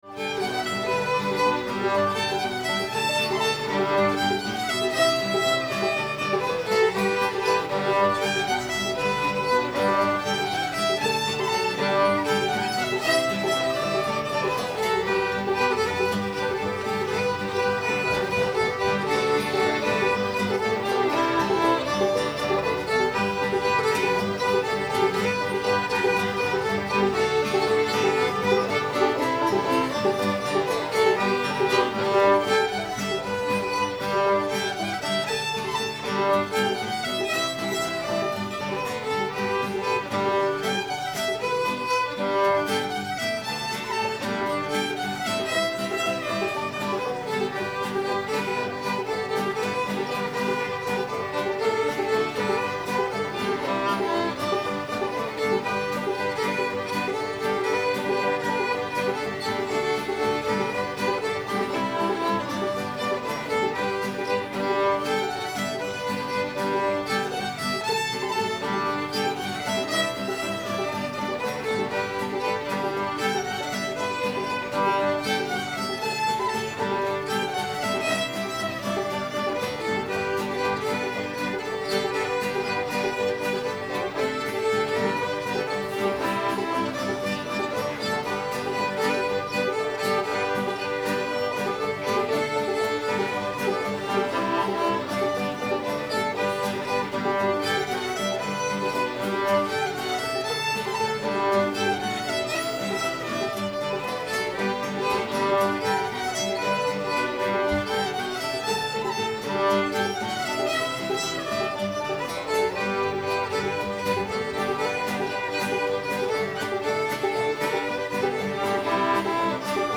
hawk's got a chicken [G]